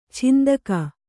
♪ chindaka